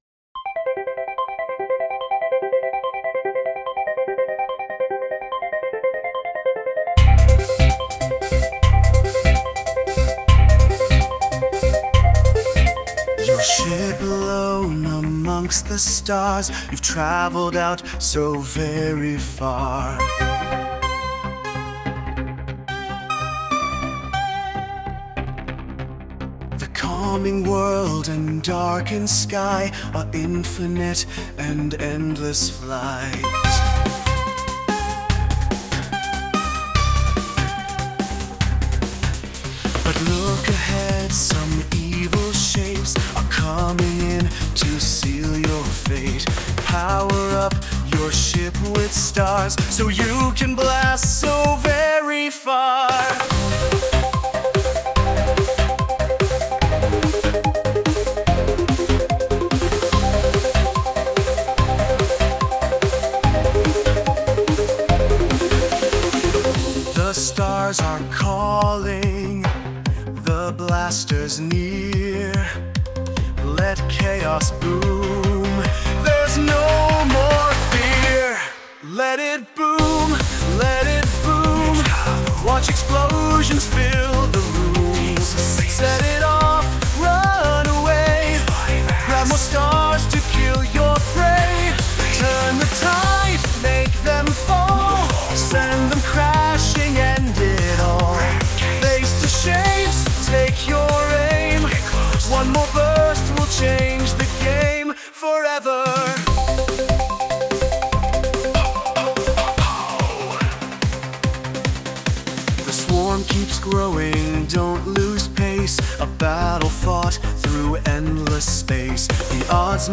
Today's lyrics are half me and half ChatGPT